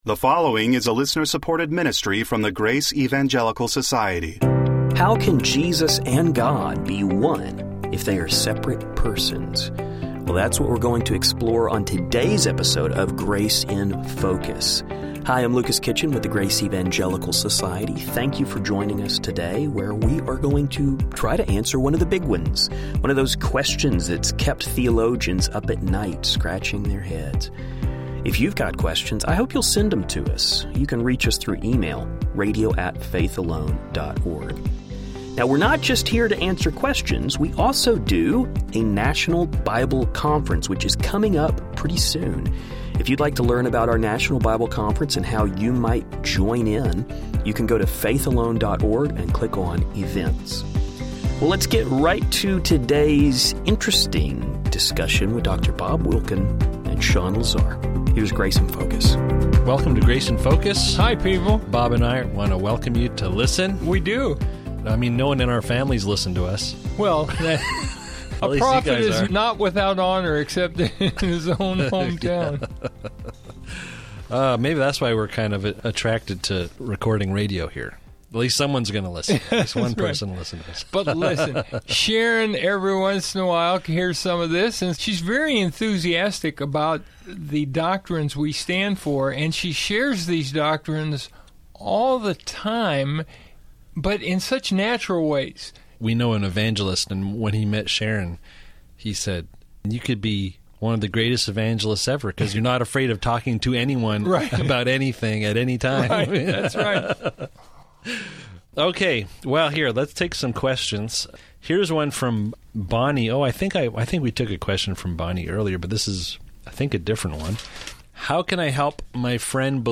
The guys will discuss this issue in light of passages such as John 1:1-2 and John 10:30. The guys will also discuss this issue in light of church history, and the council of Chalcedon. We hope you find the conversation helpful.